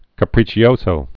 (kə-prēchē-ōsō, käprē-chōsō)